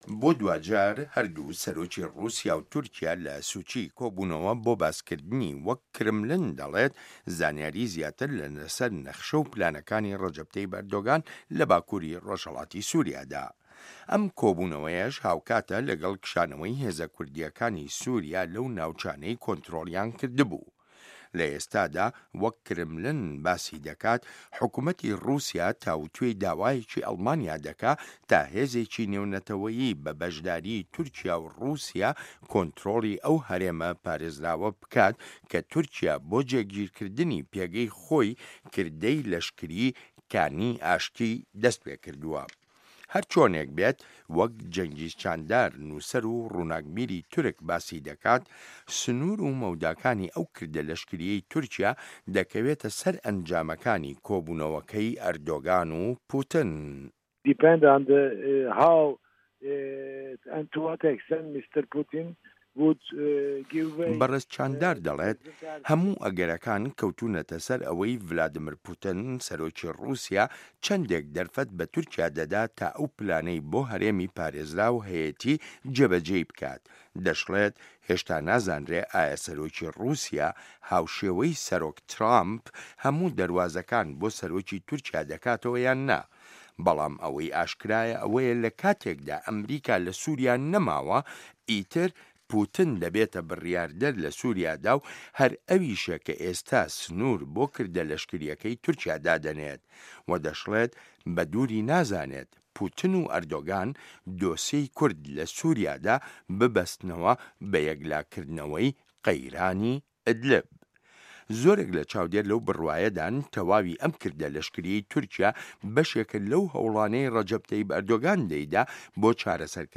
ڕۆژهه‌ڵاتی ناوه‌ڕاست - گفتوگۆکان